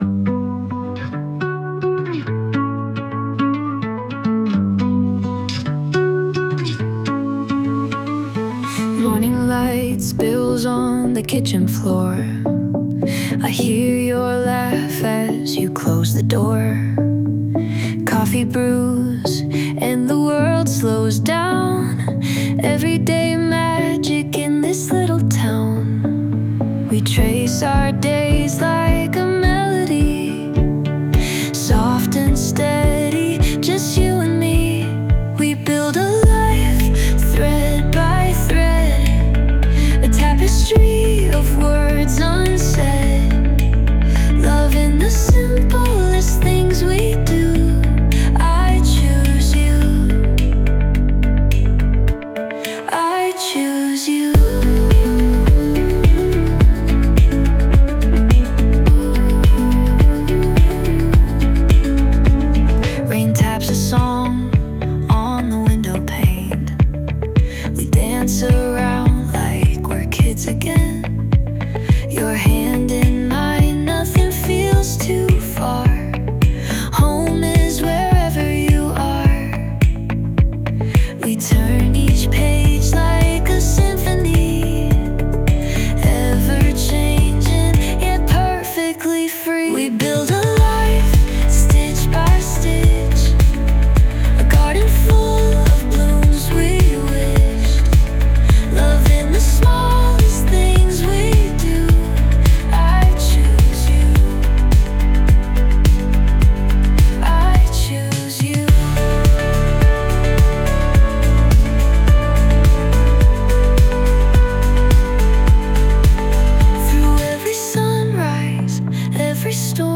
女性ボーカル洋楽 女性ボーカル
著作権フリーオリジナルBGMです。
女性ボーカル（洋楽・英語）曲です。
結婚後のほのぼのと生活をイメージした曲にしたかったですが、ちょっと切ない曲調になっちゃいました💦